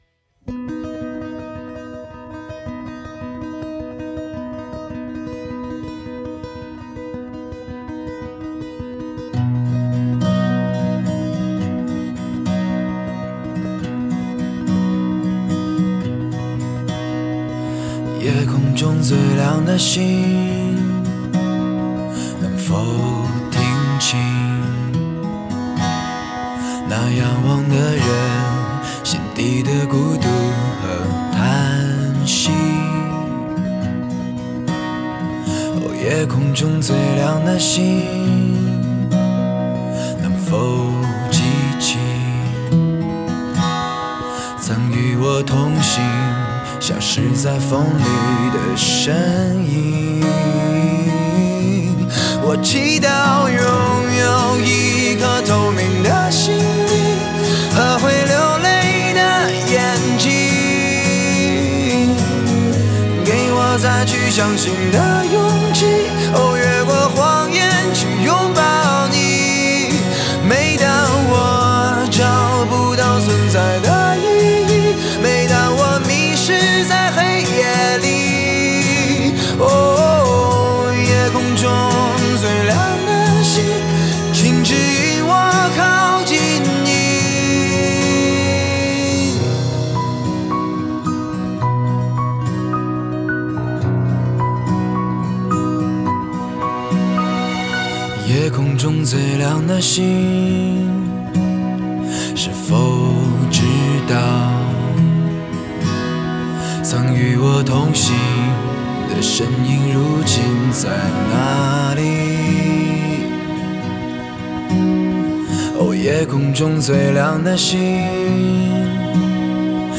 Ps：在线试听为压缩音质节选